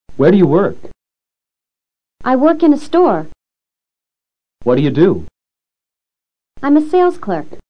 PRACTICA DE ENTONACION: Entonación ascendente y descendente.
Entonación descendente
Las preguntas (oraciones interrogativas) que no llevan respuesta con YES o NO, tienen generalmente entonación DESCENDENTE.
Por regla general, las respuestas también terminan con entonación DESCENDENTE.